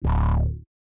Index of /RoBKTA Sample Pack Supreme/BASSES